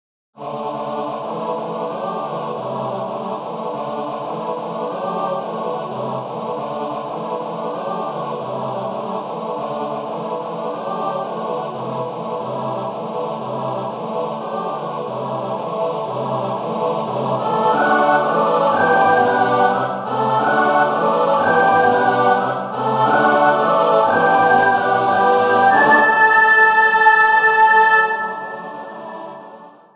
für gemischten Chor (sechsstimmig)
Beschreibung:Chormusik; Kirchenmusik; Chor geistlich